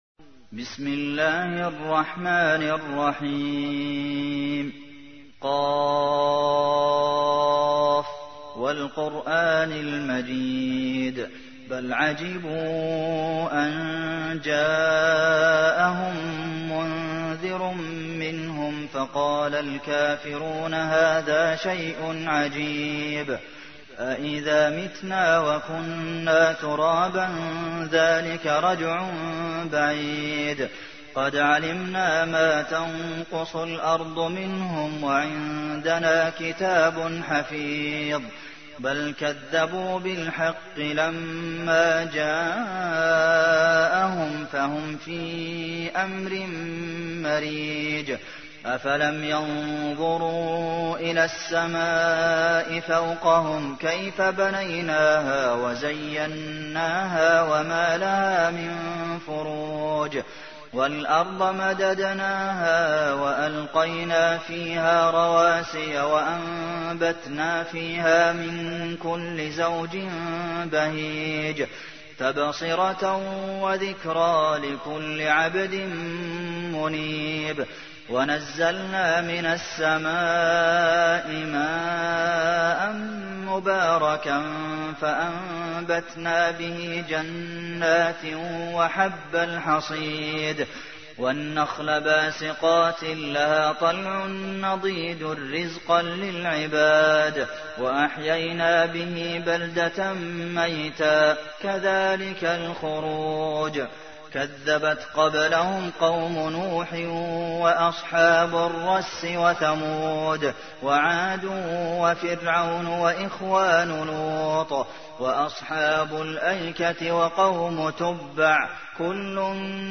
تحميل : 50. سورة ق / القارئ عبد المحسن قاسم / القرآن الكريم / موقع يا حسين